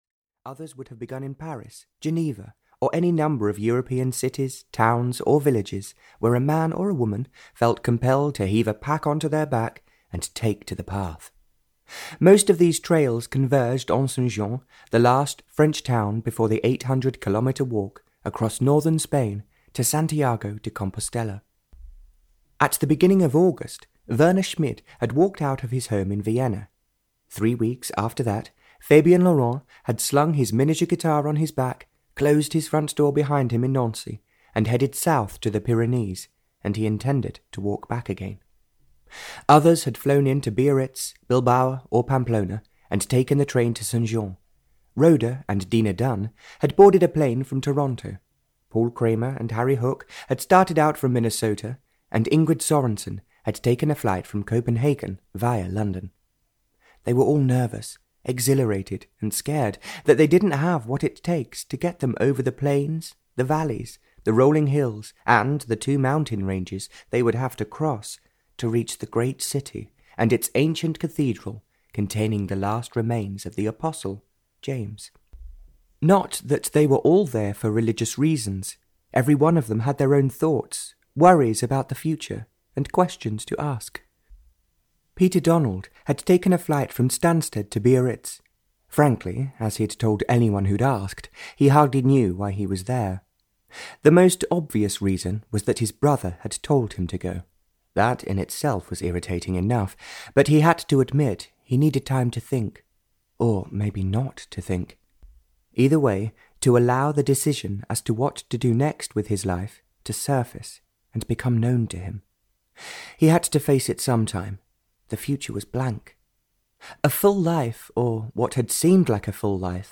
The Path (EN) audiokniha
Ukázka z knihy